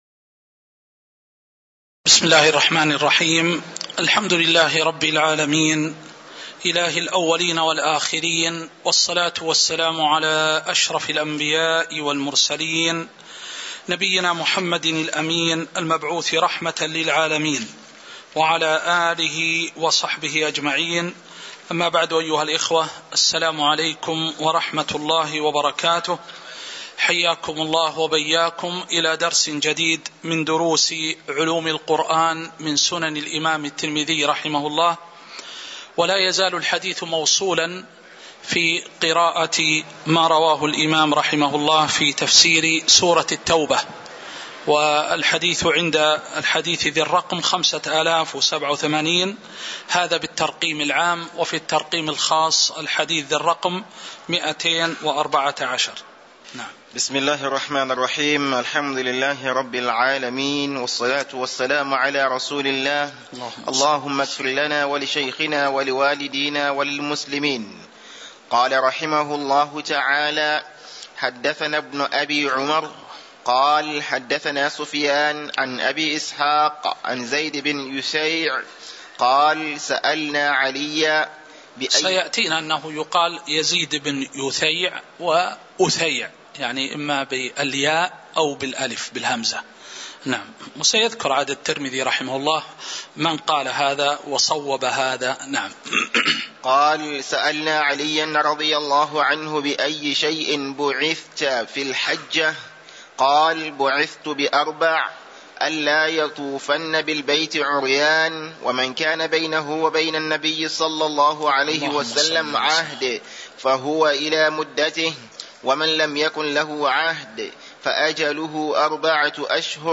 تاريخ النشر ٢٤ ربيع الثاني ١٤٤٣ هـ المكان: المسجد النبوي الشيخ